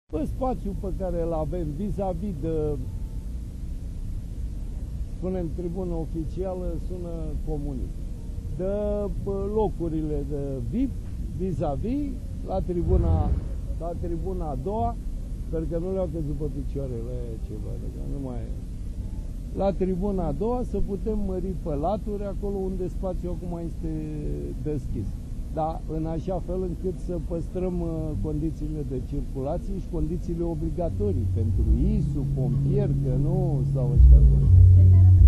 Primarul Capitalei, Sorin Oprescu, a afirmat ca, dupa ce evenimentul fotbalistic din 2012 a pus Romania pe harta sportiva a Europei, nominalizarea pentru 2020 vine ca un lucru firesc.